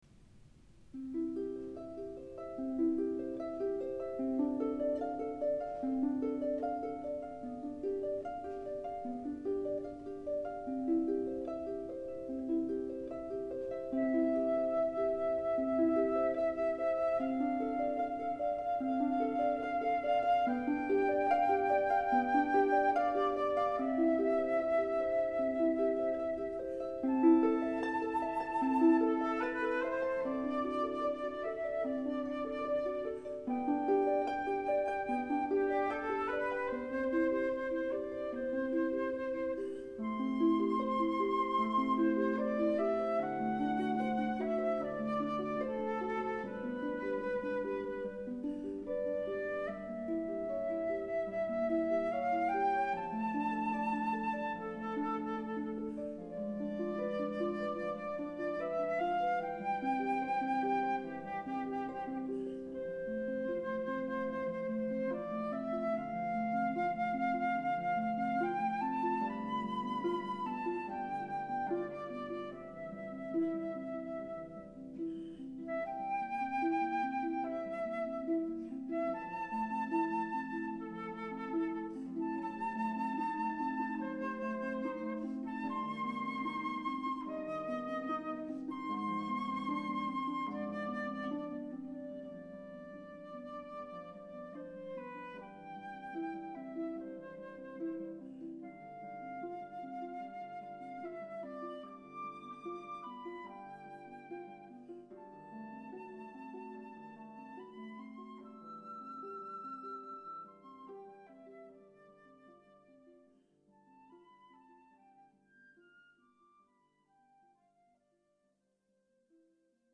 Sample (duet)